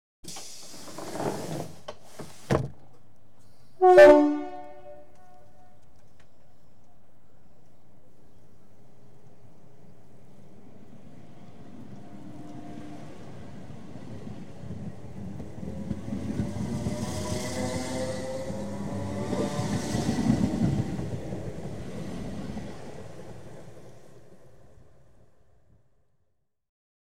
Звуки дверей поезда
Звук закрывающихся дверей, сигнал пассажирского поезда и начало движения